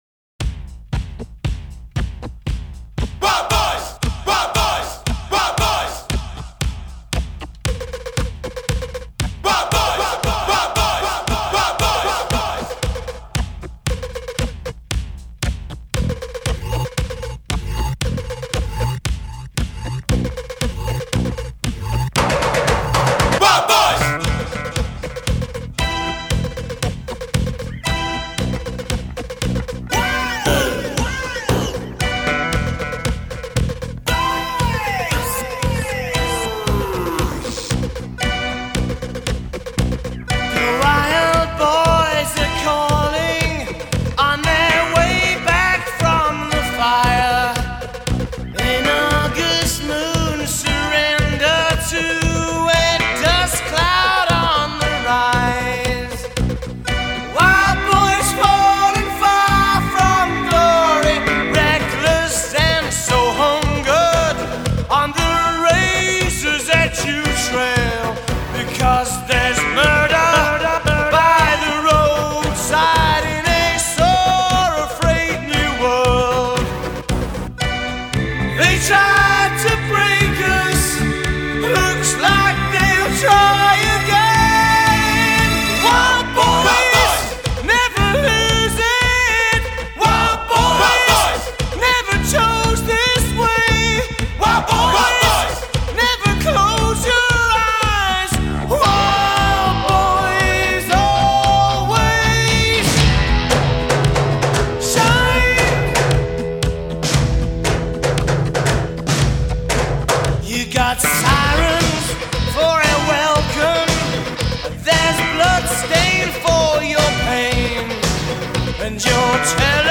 the man is rocking out